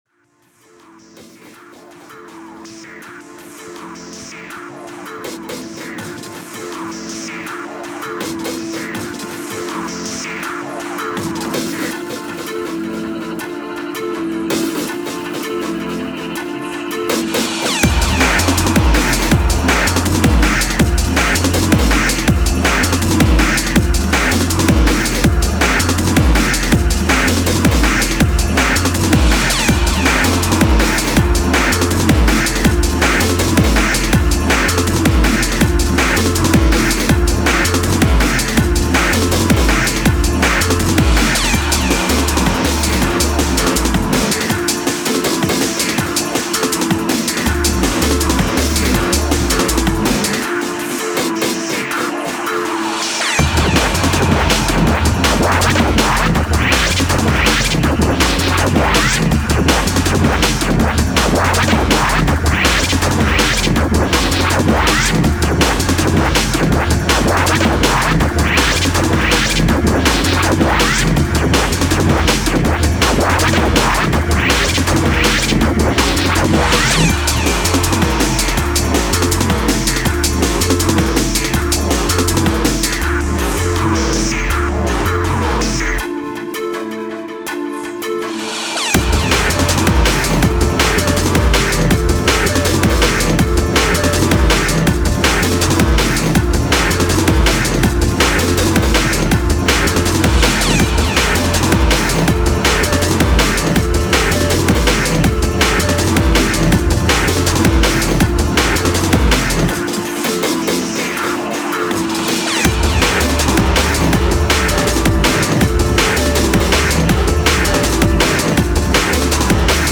OLDSKOOL DNB